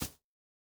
Bare Step Grass Hard A.wav